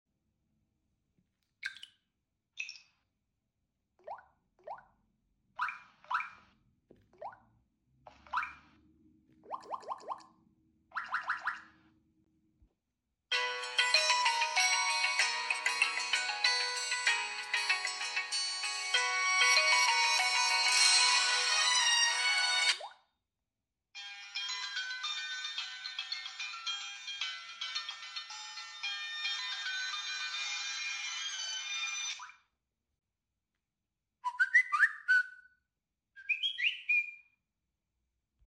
Talking Tom Cat recreates Android sound effects free download
Talking Tom Cat recreates Android 4.1.2 sounds